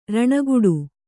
♪ raṇagu'u